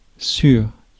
Ääntäminen
US GenAm: IPA : /ˈhɑɹmləs/ RP : IPA : /ˈhɑːmləs/